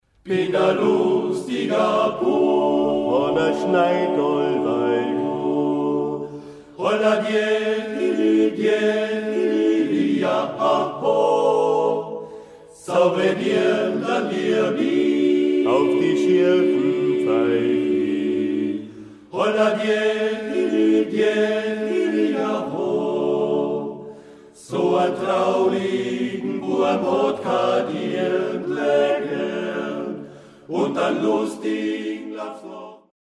• Aufgenommen im März 2005 in der Volksschule Poggersdorf
Kleingruppe